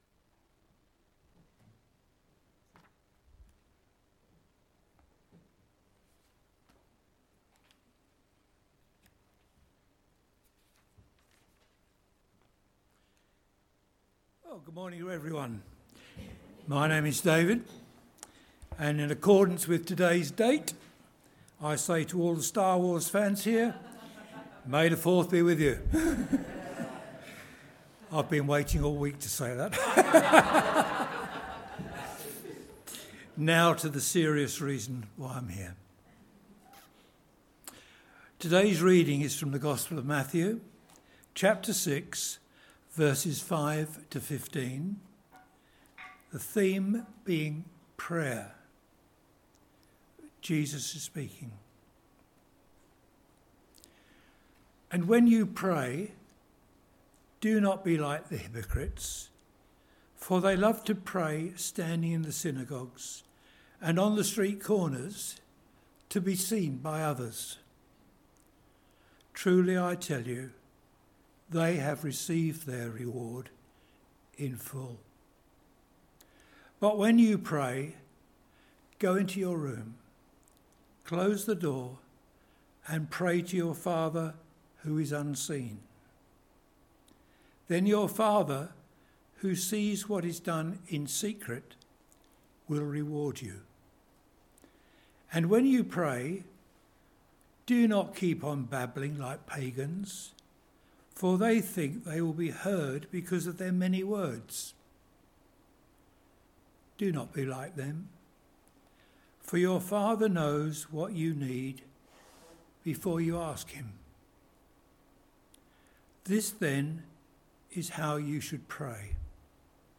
Sermon on the Mount Part 12 - Prayer | Pathway to Life API